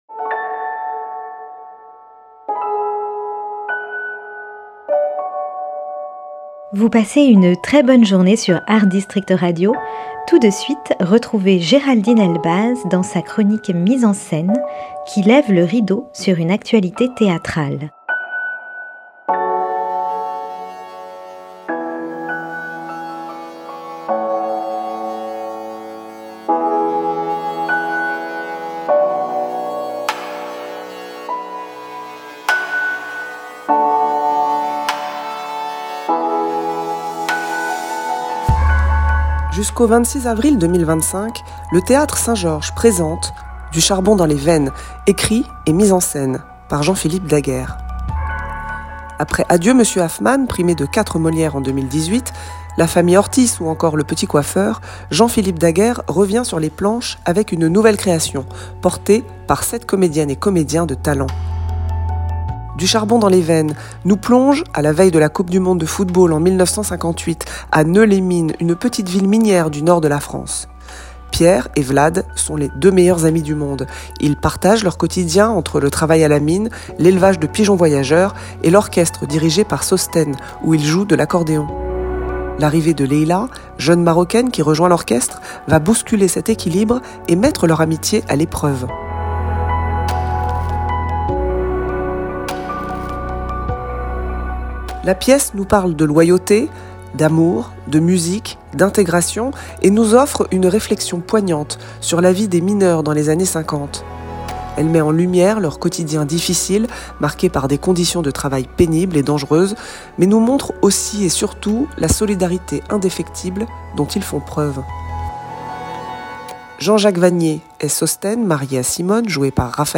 Chronique théâtrale